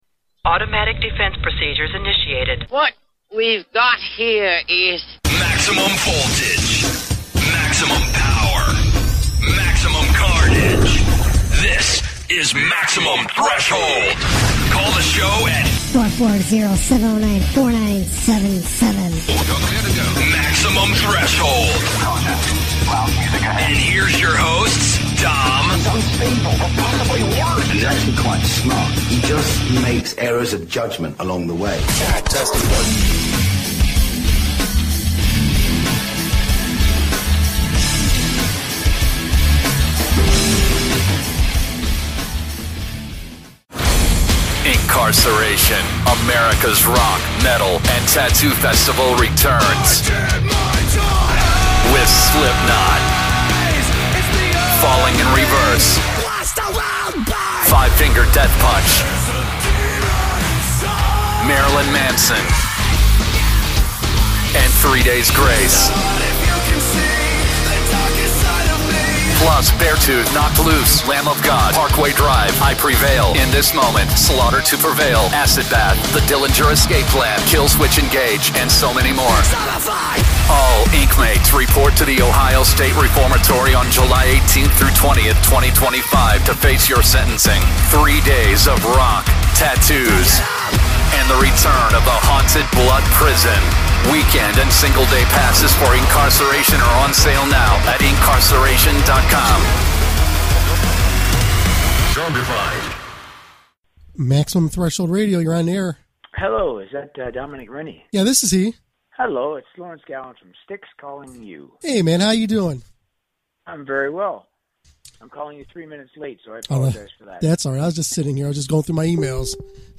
A great interview. Played the Rock and Metal News segment.